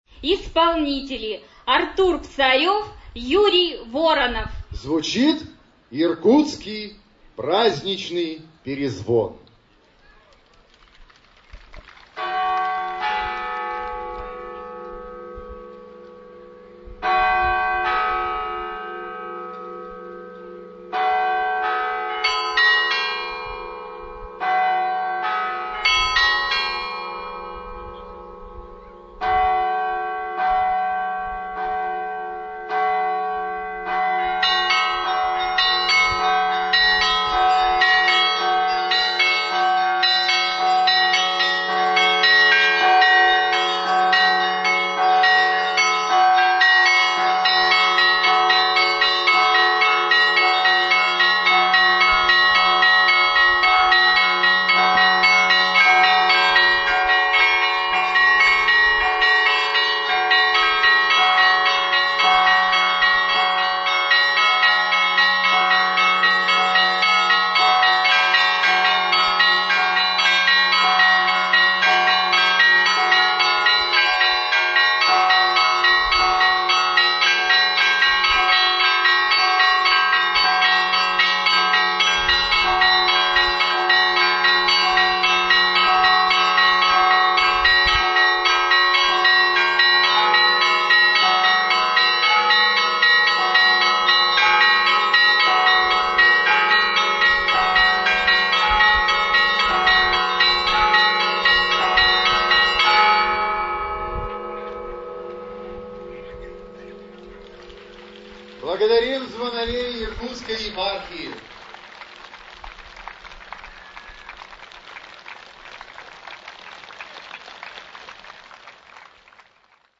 Каменск-Уральский - колокольная столица 2006
Гала-концерт фестиваля
Иркутский праздничный перезвон